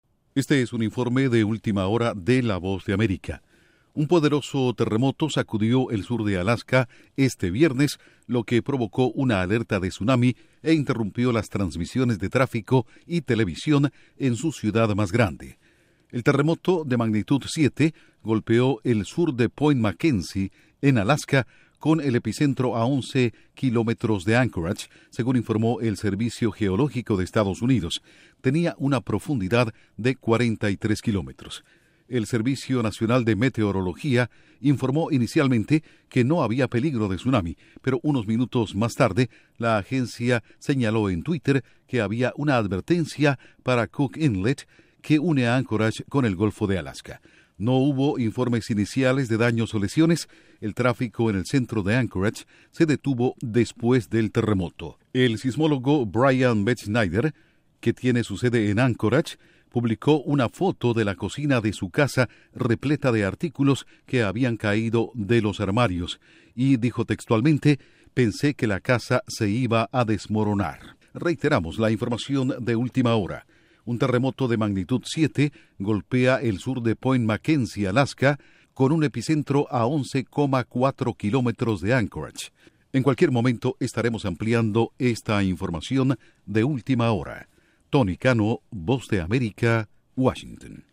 VOA: Última Hora: Terremoto de magnitud 7 en Alaska, emiten alerta de tsunami